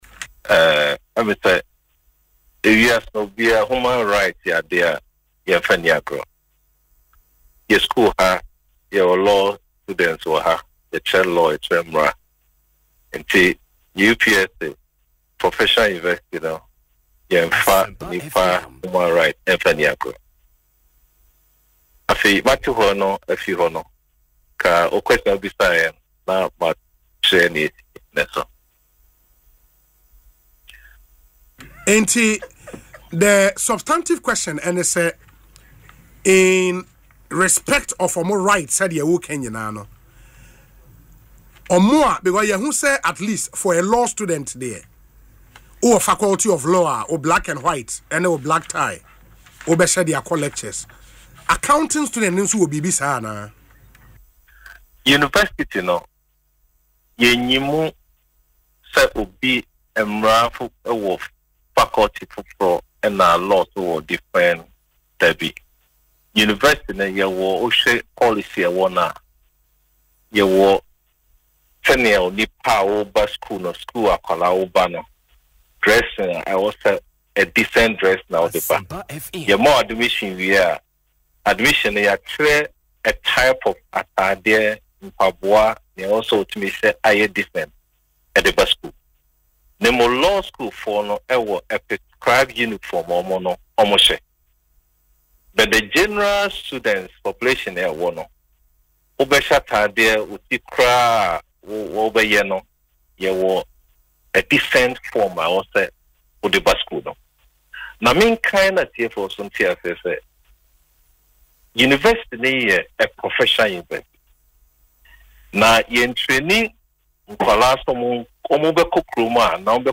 Speaking in an interview on Asempa FM’s Ekosii Sen